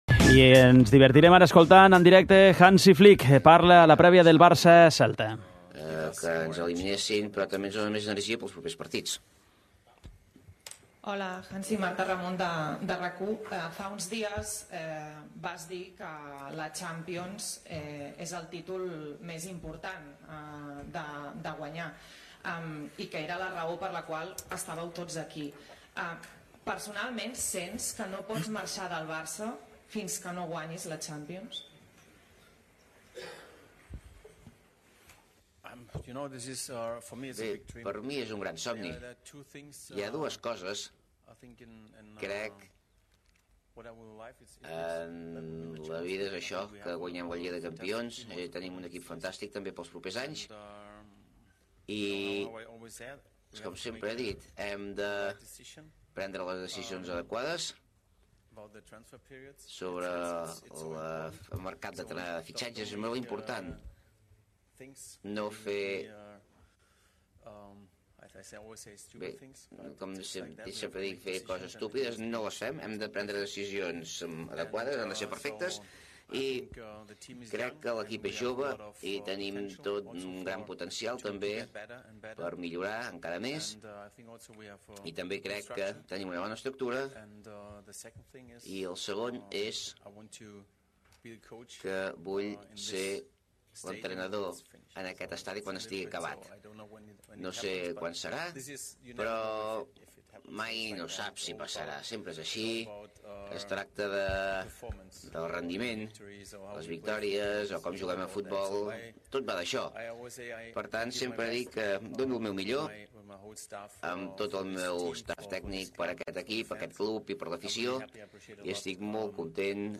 Al 'Què T'hi Jugues!' escoltem la roda de premsa de Hansi Flick prèvia al partit contra el Celta